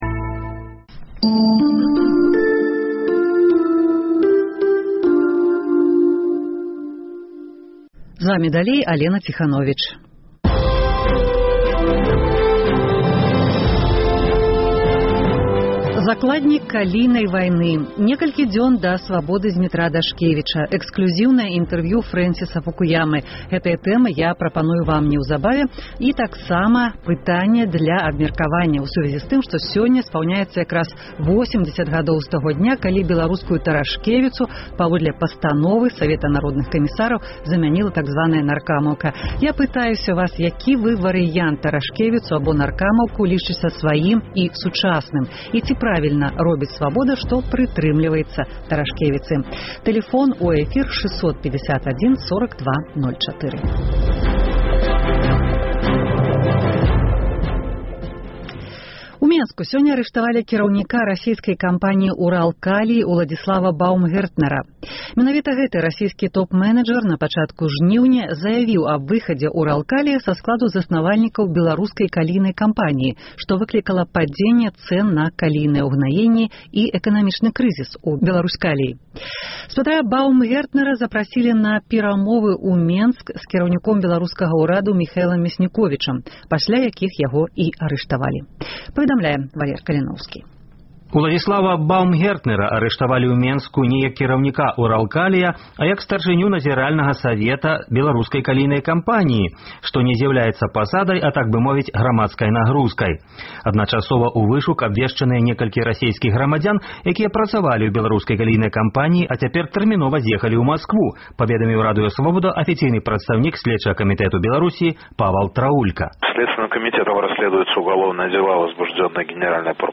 Закладнік калійнай вайны, магіла невядомага “тэрарыста” і прынцыповая настаўніца. Гэтыя і іншыя тэмы запрашаю абмяркоўваць у жывым эфіры. Пытаньне дня: ці трэба паведамляць сям’і, дзе пахаваны пакараны сьмерцю?